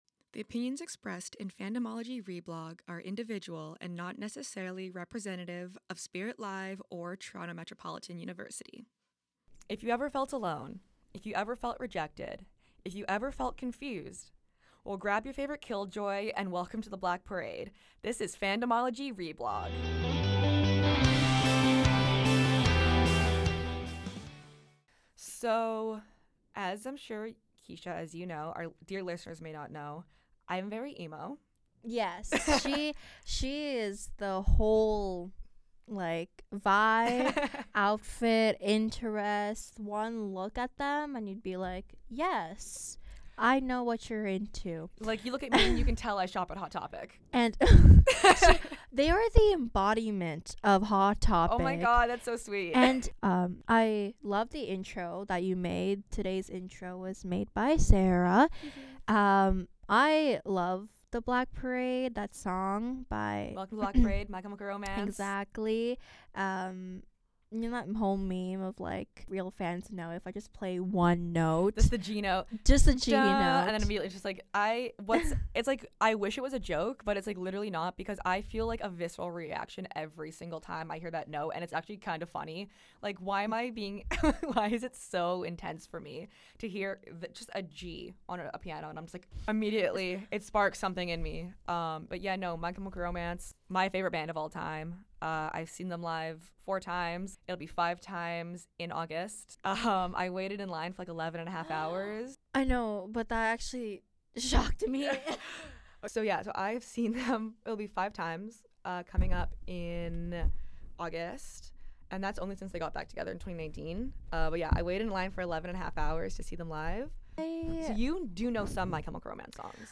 This second episode of Fandomology: Reblog includes our take on the MCU, mainly on Captain America and James Buchanan Barnes. Although this episode title indicated mumbling– there is in fact no mumbling and rather immense rage and chaos…